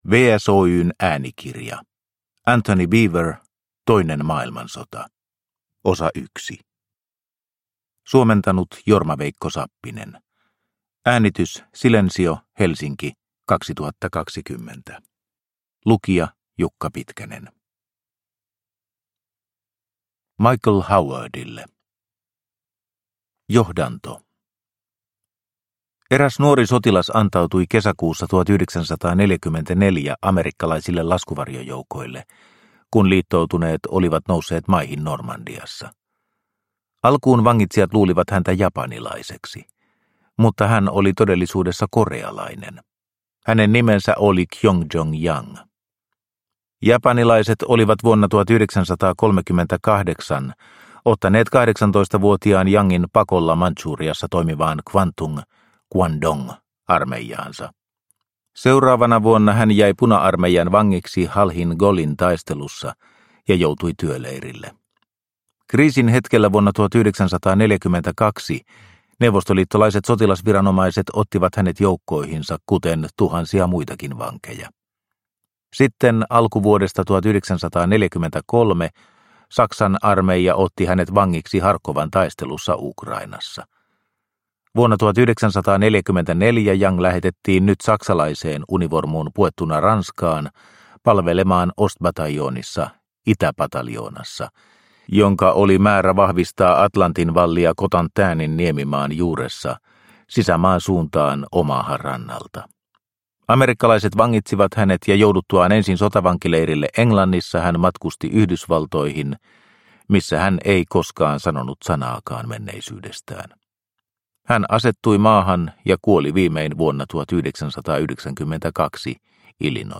Toinen maailmansota, osa 1 – Ljudbok – Laddas ner